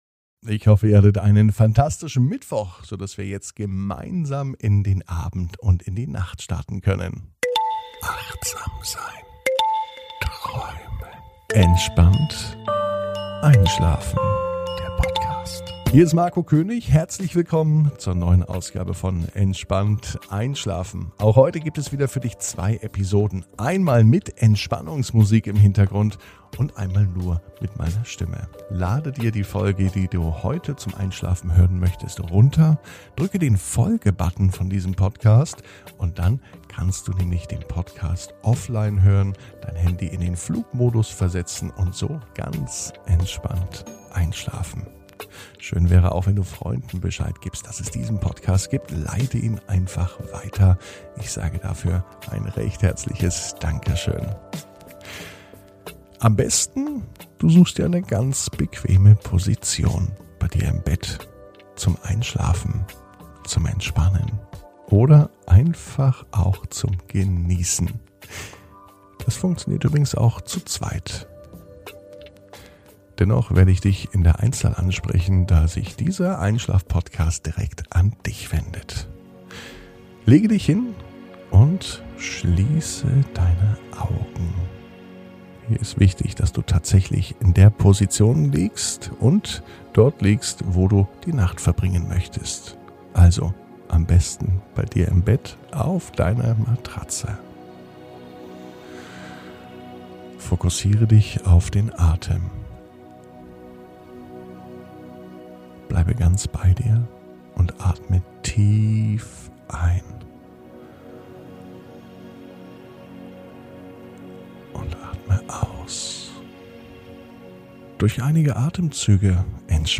Ab jetzt ist keine Zeit mehr für unruhigen Schlaf und Einschlafstörungen. Heute startet der neue Einschlafpodcast Entspannt einschlafen.